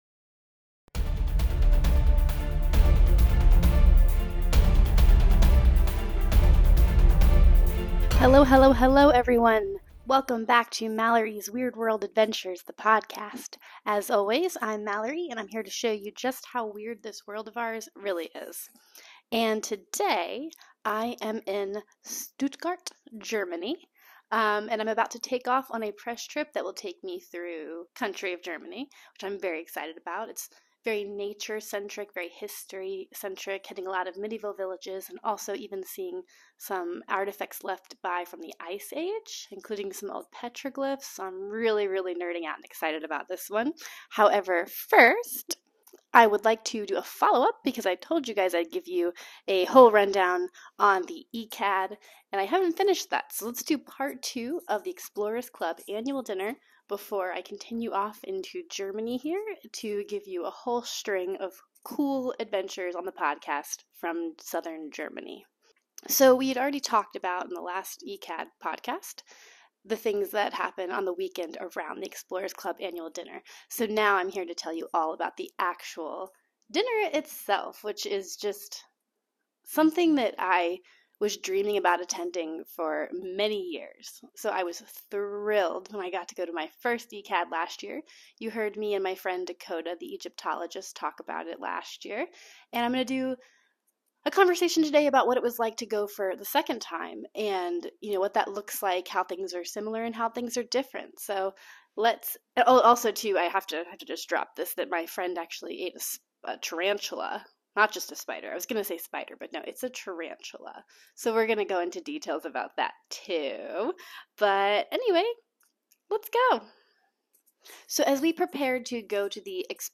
bear with the short episode and raspy voice